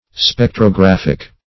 Spec`tro*graph"ic, a. -- Spec`tro*graph"ic*al*ly,